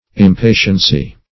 \Im*pa"tien*cy\ ([i^]m*p[=a]"shen*s[y^]), n.